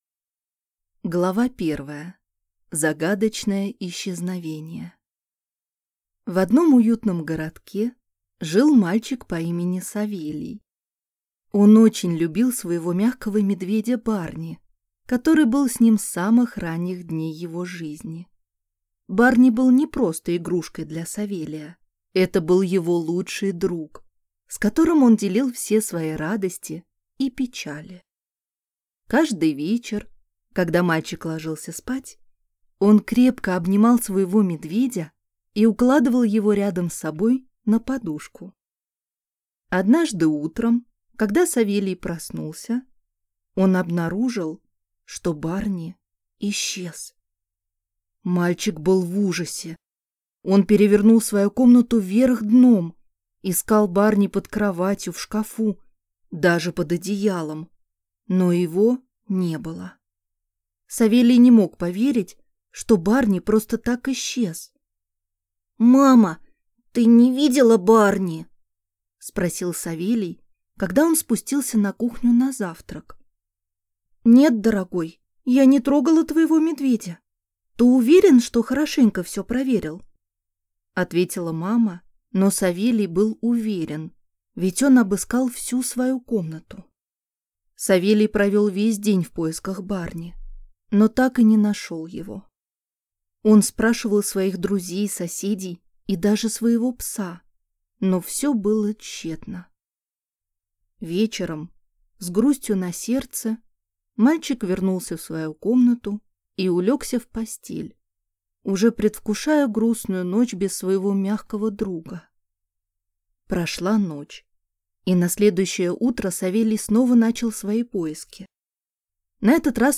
Аудиокнига Путешествие в Страну Мягких Игрушек | Библиотека аудиокниг